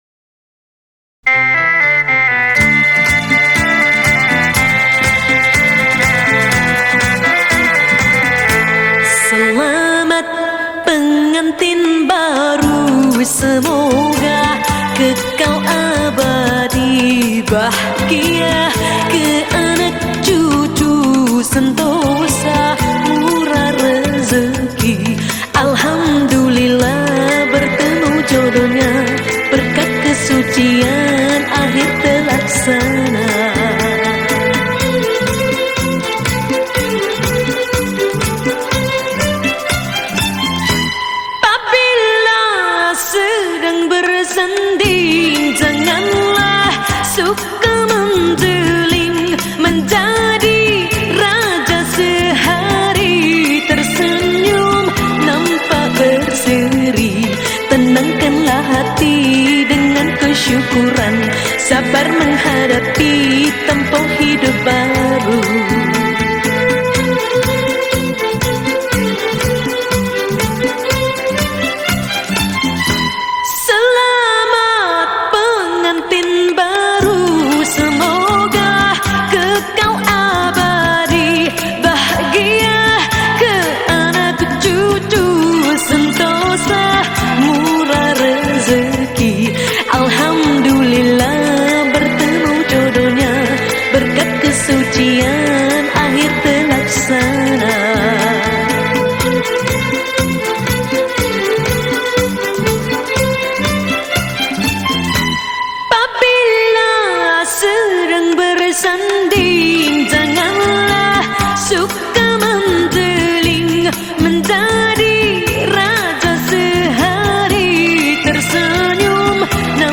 Malay Song
Skor Angklung